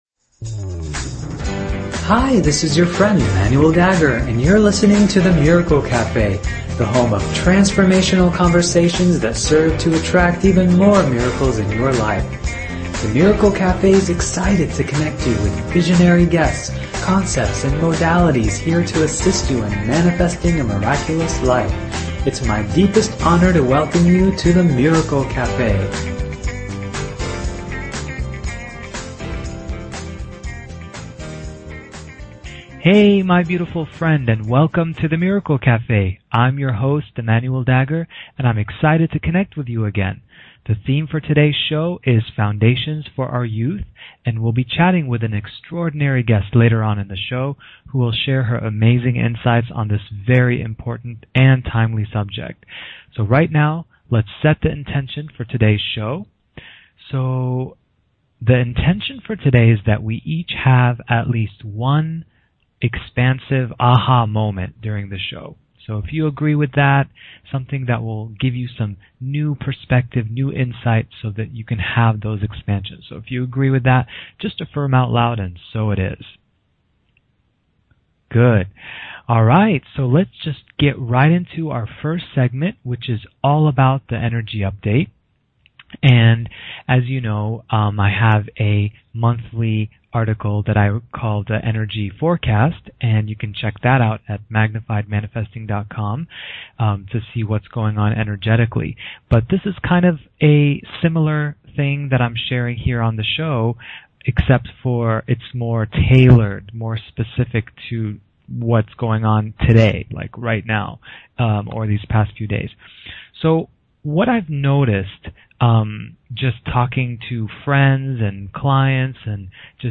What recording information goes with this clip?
The Miracle Café is a radio show that is here to provide each listener with life-changing insights, processes, and conversations that serve to attract even more miracles and positive transformation in their life!